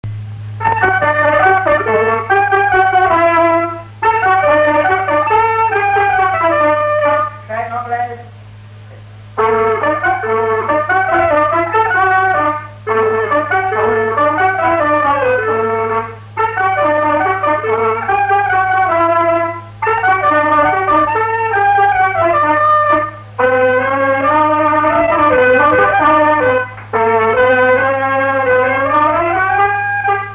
instrumental
danse
Pièce musicale inédite